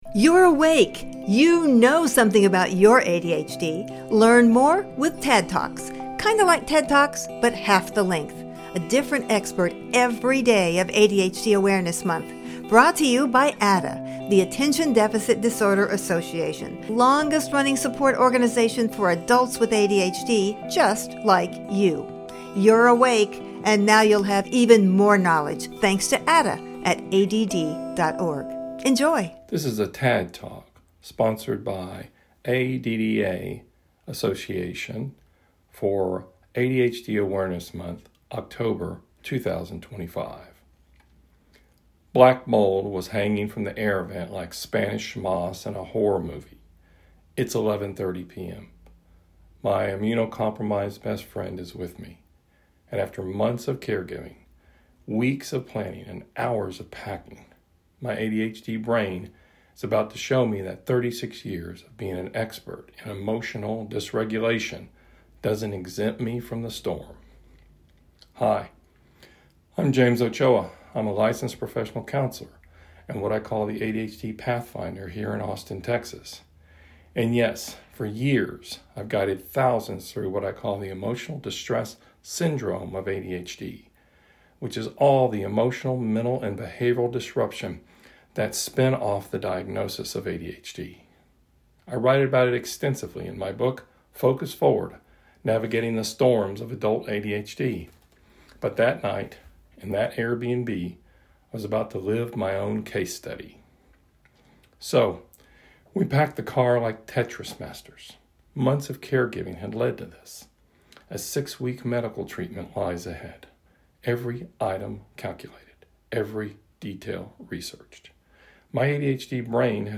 TADD TALK